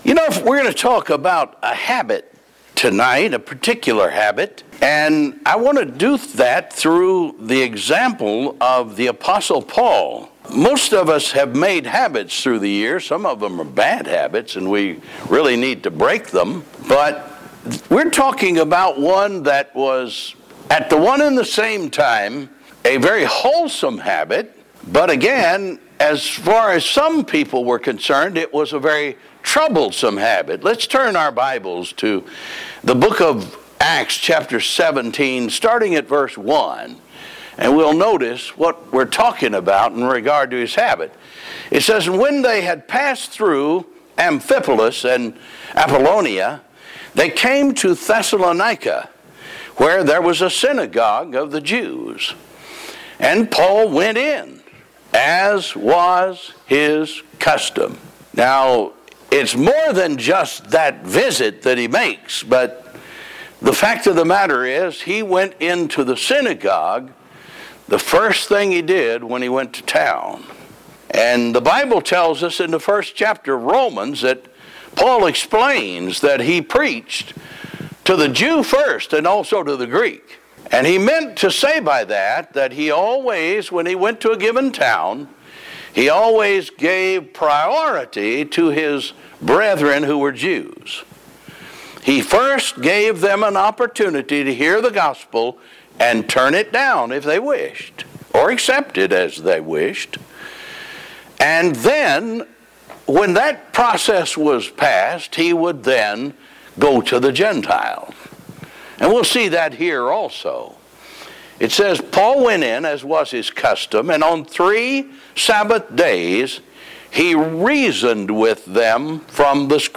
Soul Winning ← Newer Sermon Older Sermon →